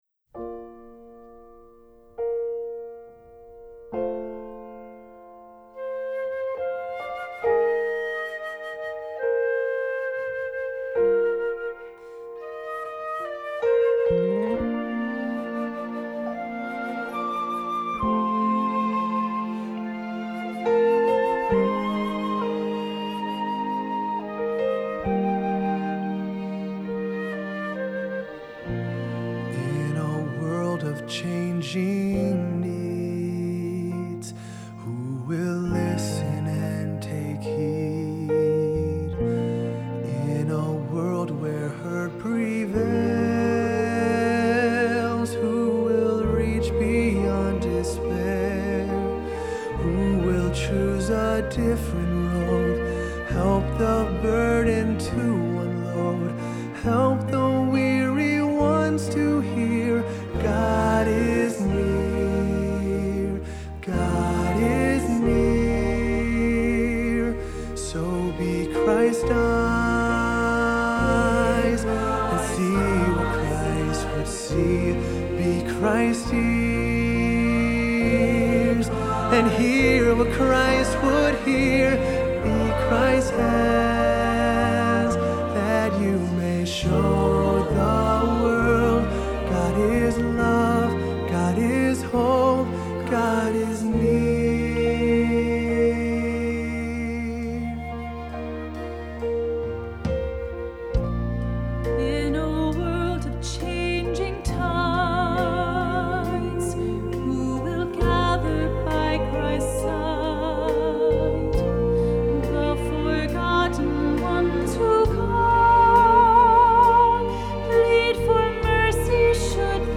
Accompaniment:      Keyboard, Flute
Music Category:      Christian